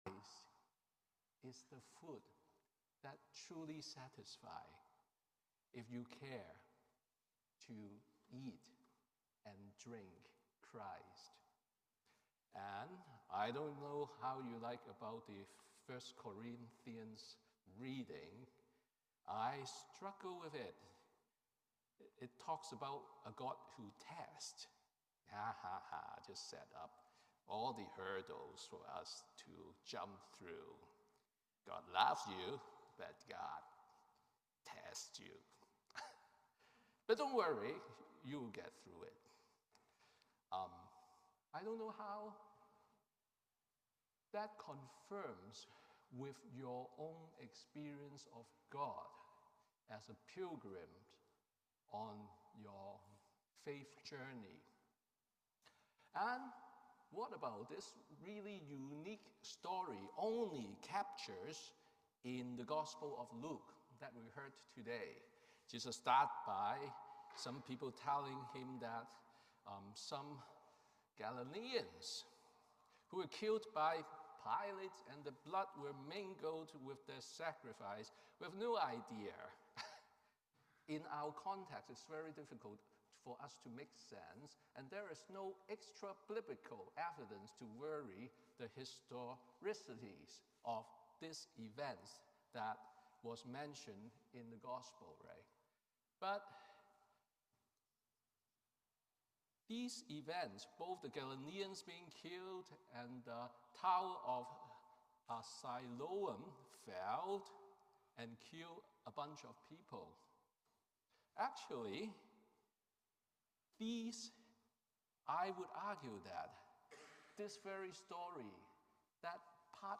Sermon on the Third Sunday in Lent